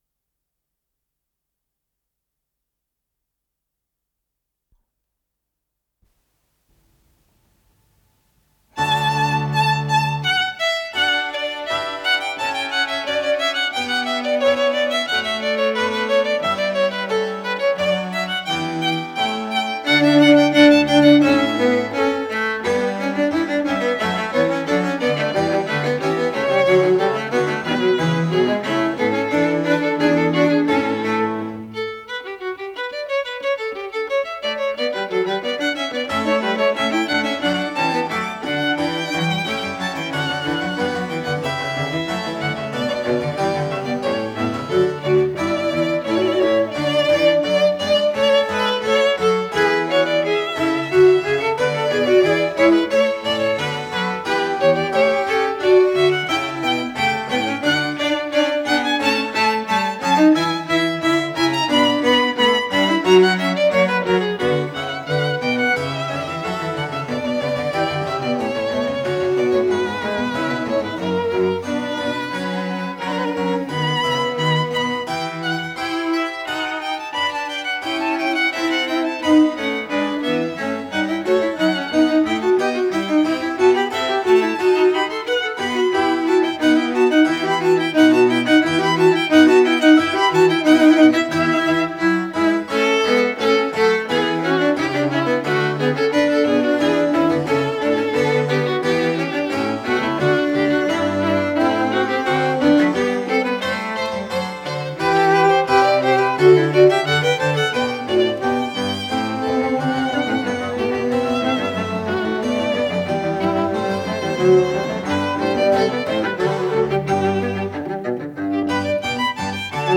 с профессиональной магнитной ленты
Аллегро
скрипка
виолончель соло
клавесин
виолончель континуо